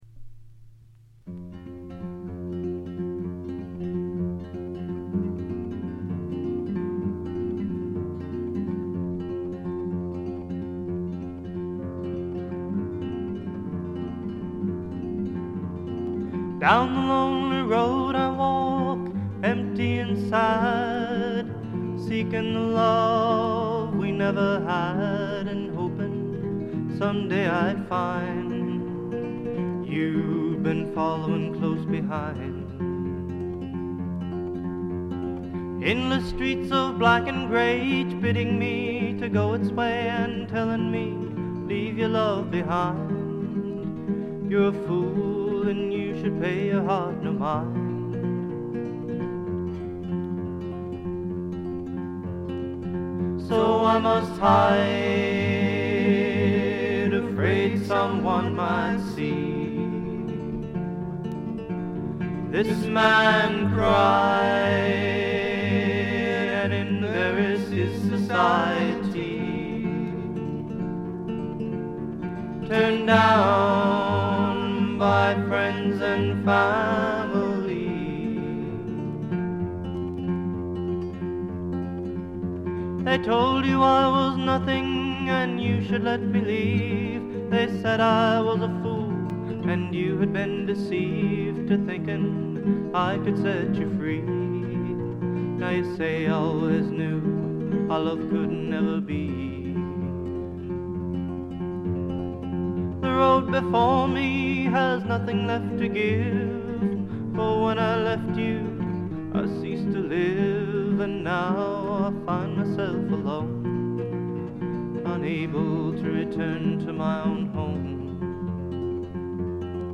わずかなノイズ感のみ。
全10曲すべて自作、ほとんどギターの弾き語りで、しみじみとしたロンサムな語り口が印象的です。
試聴曲は現品からの取り込み音源です。
Soprano Vocals